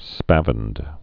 (spăvĭnd)